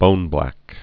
(bōnblăk)